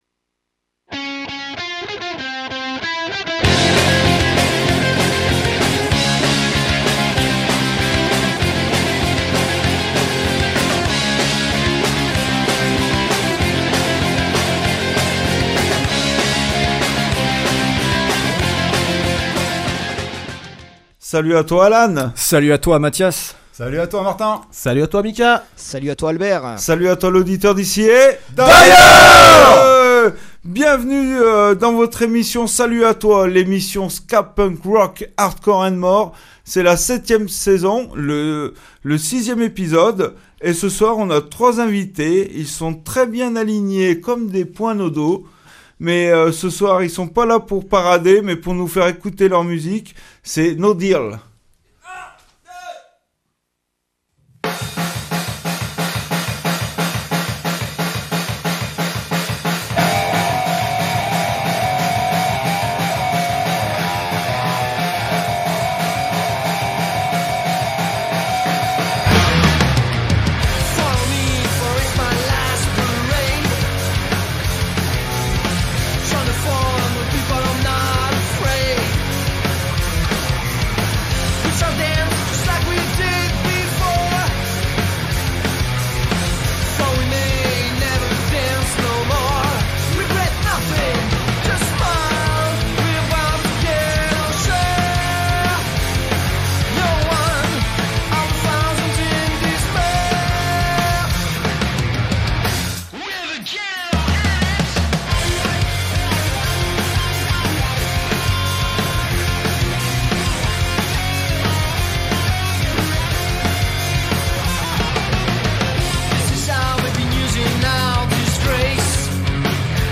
7 eme saison de salut à toi , l'émission ska punk rock hardcore and more...